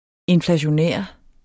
Udtale [ enflaɕoˈnεˀɐ̯ ]